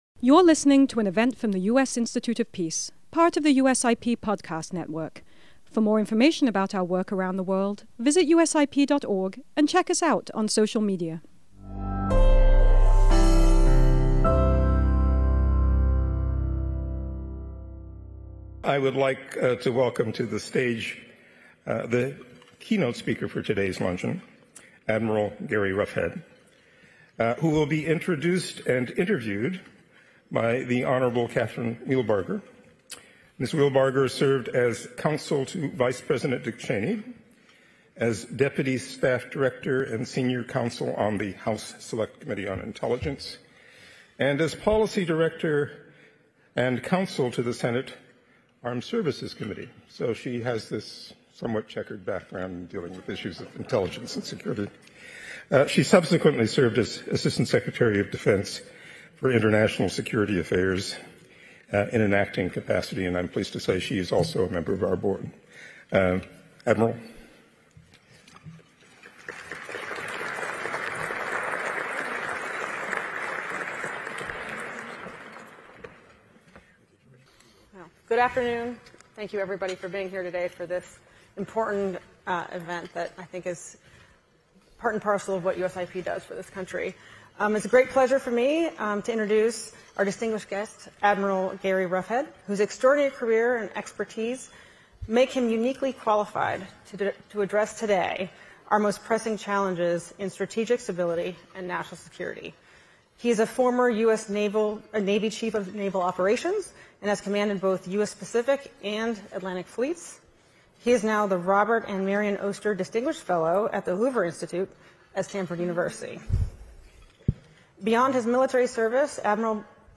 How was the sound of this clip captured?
This flagship, bipartisan event brought together national security leaders from across the political spectrum to mark the peaceful transfer of power and the bipartisan character of American foreign policy.